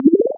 maximize_006.ogg